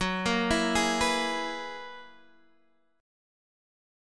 F#11 Chord
Listen to F#11 strummed